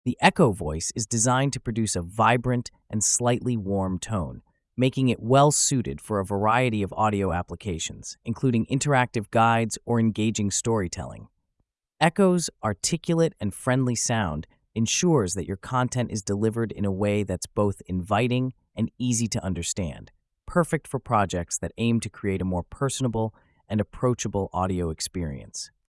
The “Echo” voice is designed to produce a vibrant and slightly warm tone, making it well-suited for a variety of audio applications, including interactive guides or engaging storytelling. Echo’s articulate and friendly sound ensures that your content is delivered in a way that’s both inviting and easy to understand, perfect for projects that aim to create a more personable and approachable audio experience.